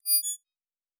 pgs/Assets/Audio/Sci-Fi Sounds/Interface/Error 03.wav at master
Error 03.wav